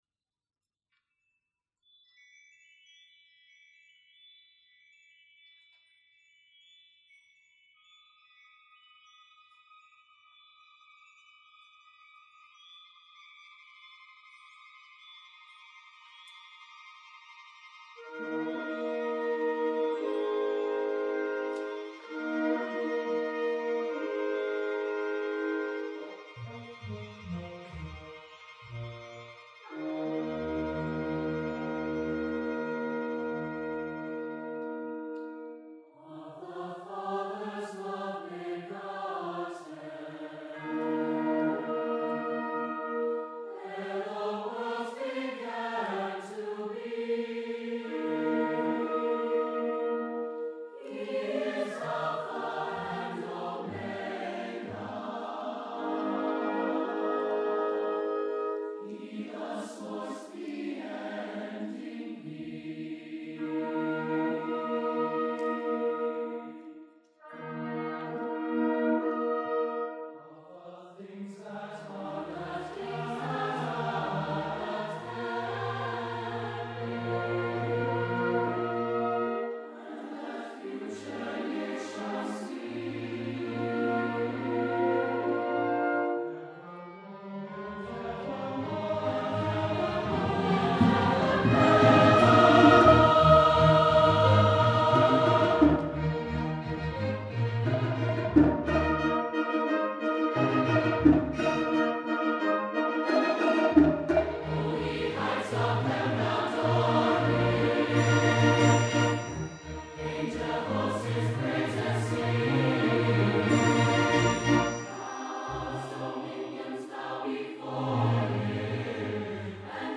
for SATB Chorus and Orchestra (2009)
orchestra version